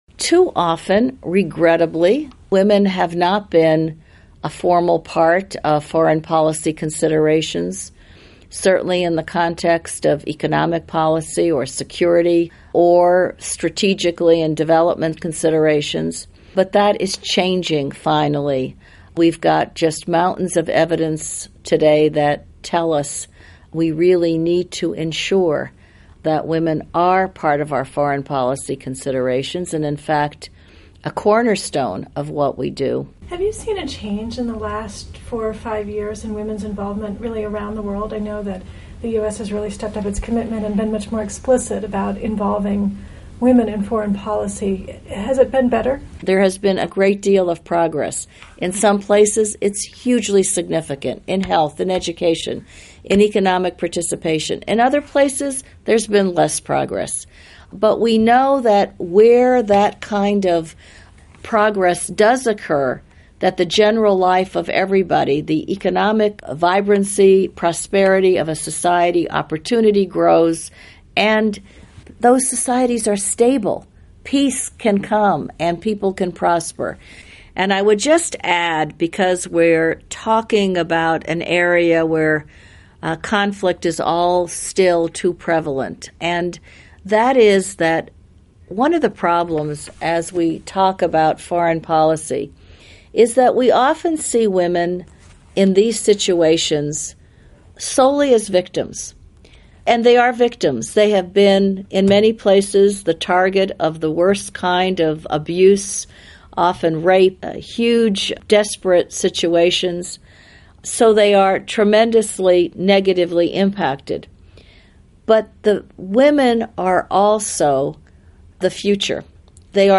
Q&A with Global Ambassador for Global Women's Issues Melanne Verveer